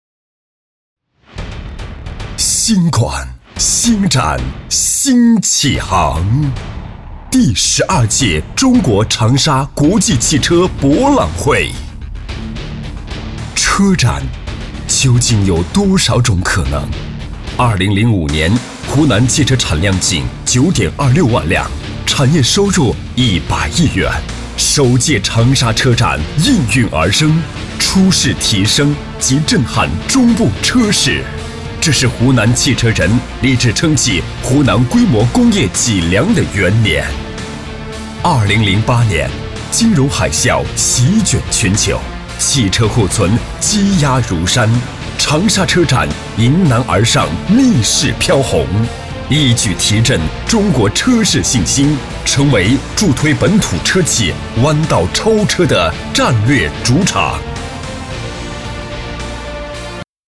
19男-大气激情-集团宣传
擅长：专题片 广告
特点：大气浑厚 稳重磁性 激情力度 成熟厚重